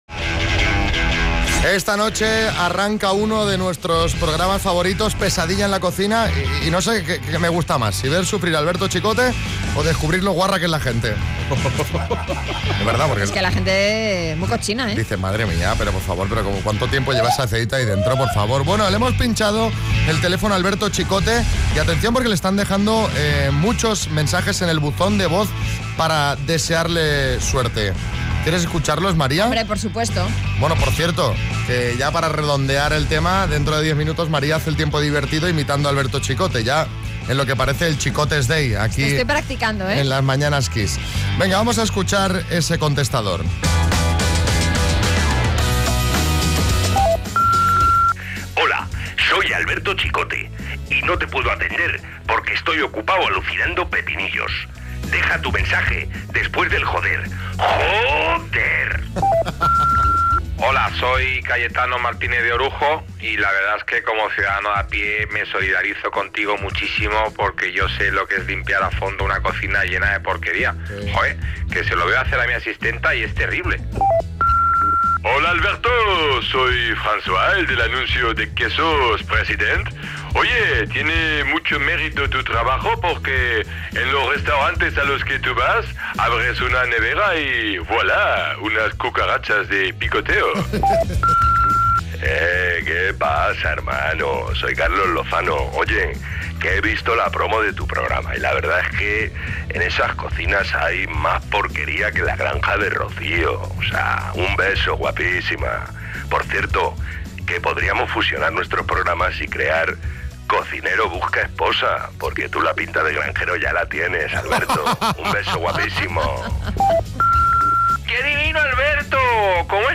El contestador de Chicote
Esta noche Chicote estrena una nueva temporada de «Pesadilla en la cocina» y hemos tenido acceso al contestador de su móvil. Carlos Lozano, Boris Izaguirre y Matías Prats son algunos de los amigos que han querido desearle suerte en su estreno.